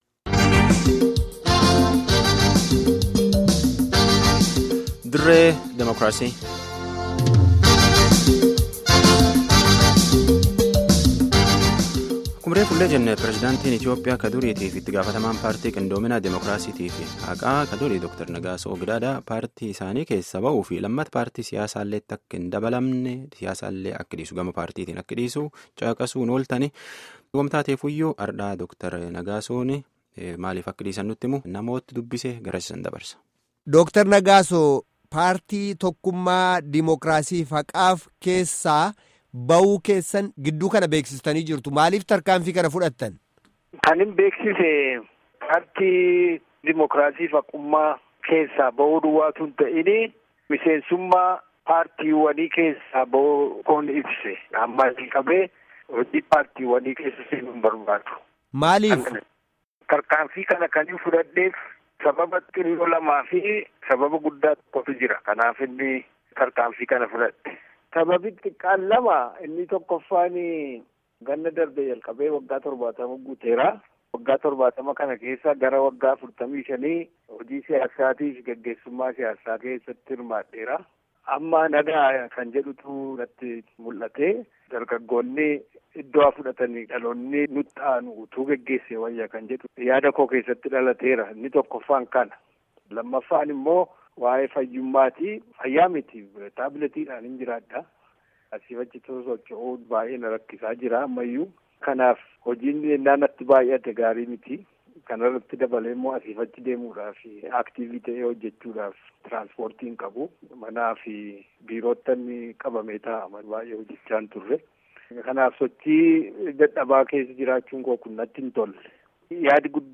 Guutummaa gaaffii fi deebii kanaa dhaggeeffadhaa